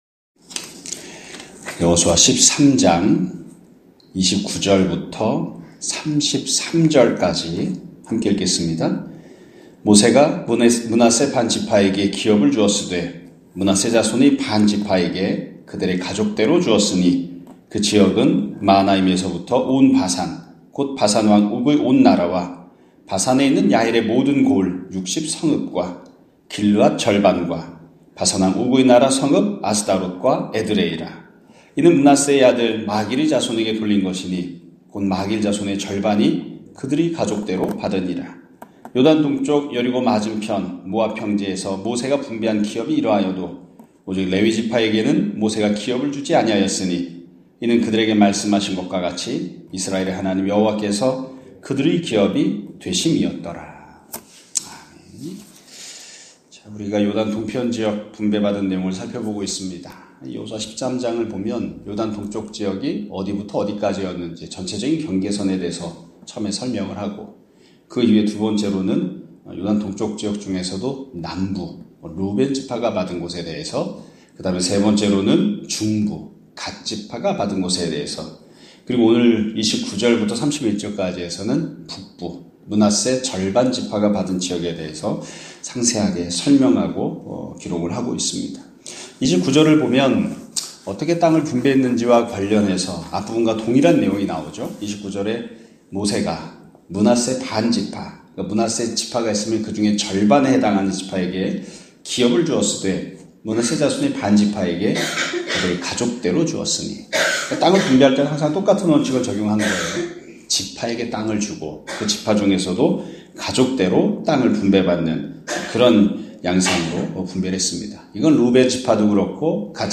2024년 11월 22일(금요일) <아침예배> 설교입니다.